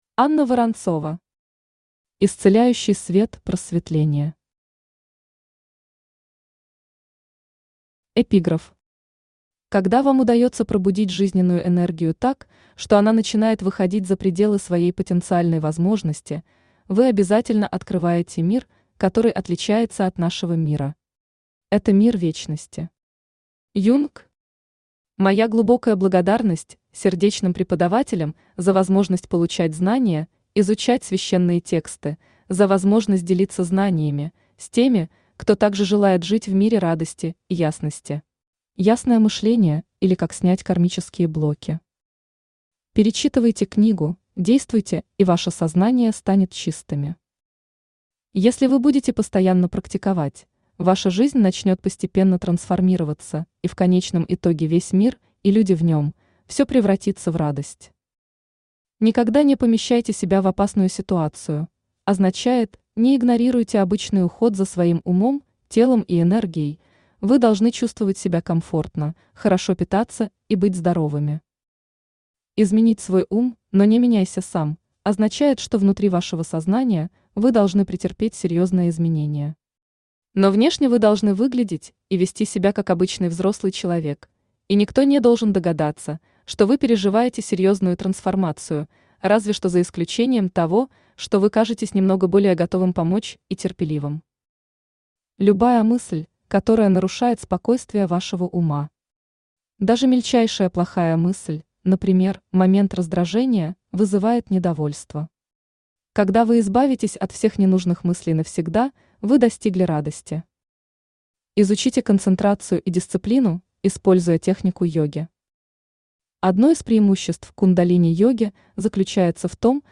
Aудиокнига Исцеляющий свет просветления Автор Анна Борисовна Воронцова Читает аудиокнигу Авточтец ЛитРес.